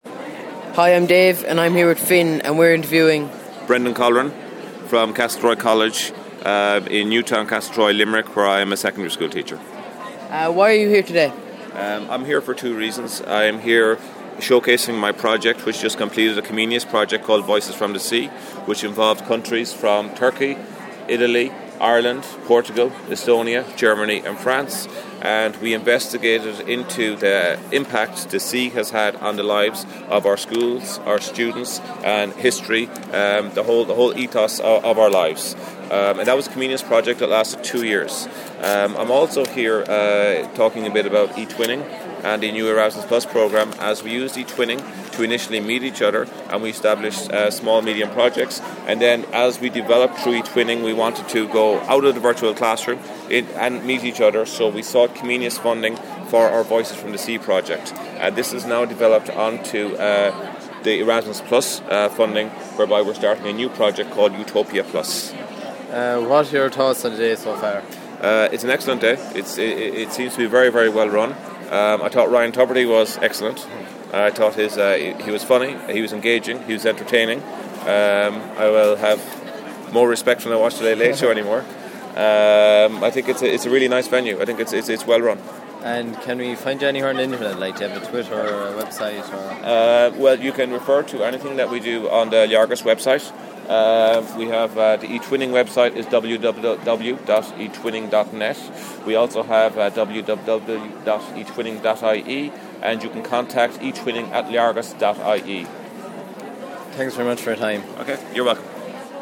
In the RDS, Ballsbridge, Dublin, Ireland